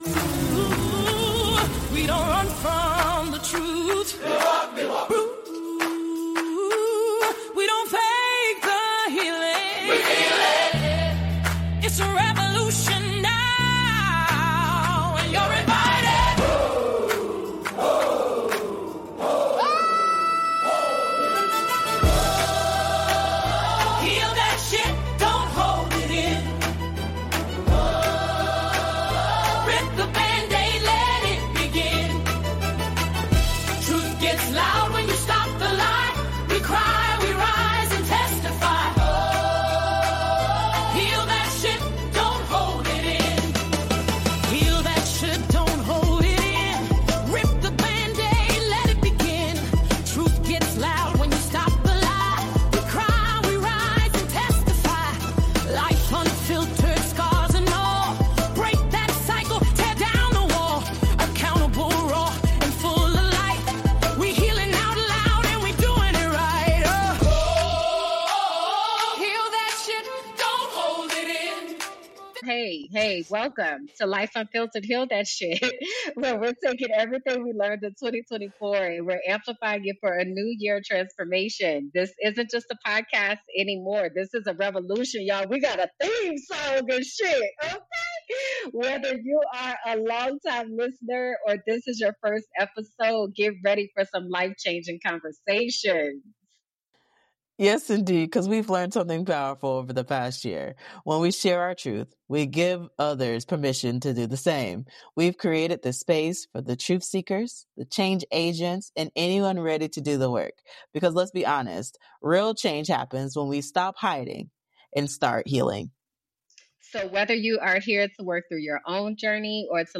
From navigating burnout to balancing purpose with personal life, they keep it real about the cost and the calling of speaking out. They share personal stories of finding strength through community, the role creativity plays in advocacy, and why accountability, transparency, and integrity are non-negotiable. With humor, heart, and a whole lot of truth-telling, this conversation is a reminder that healing is a process we walk together.